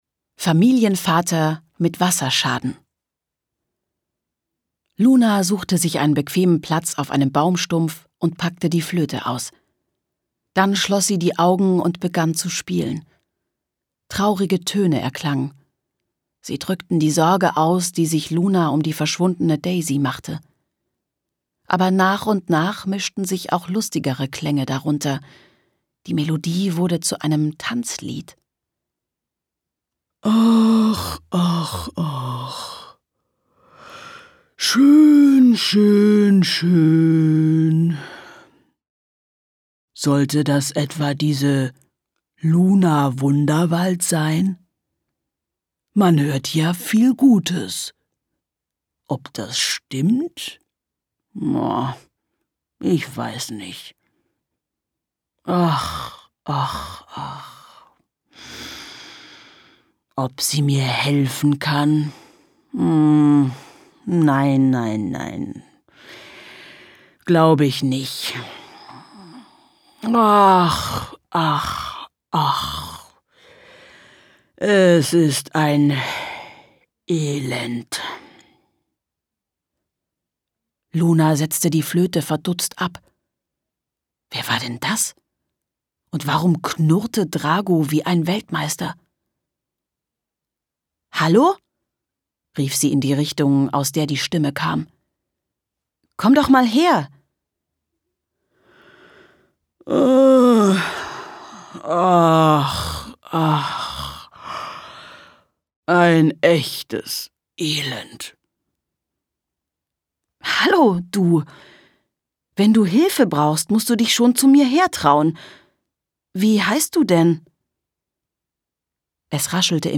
Hörbuch: Luna Wunderwald.